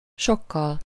Ääntäminen
IPA : [ˈmʌtʃ]